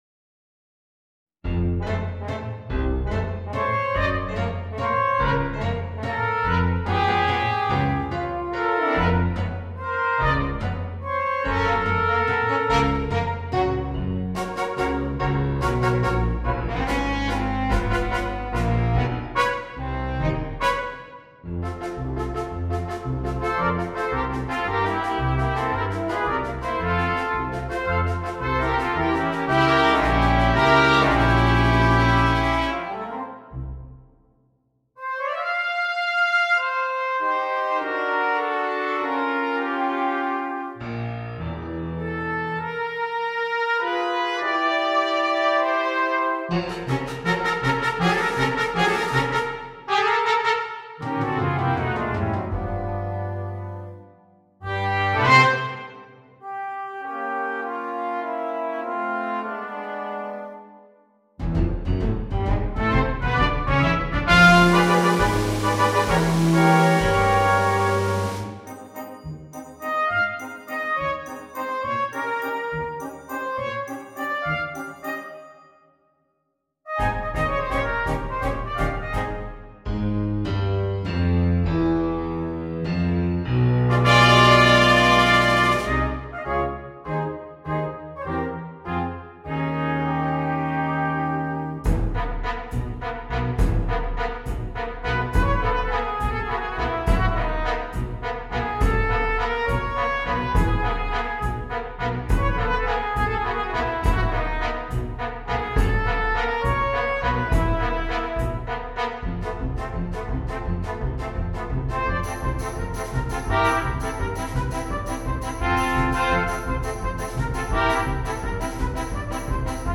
Gattung: Brass Quartet
Besetzung: Ensemblemusik für 4 Blechbläser